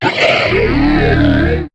Cri de Tarinorme dans Pokémon HOME.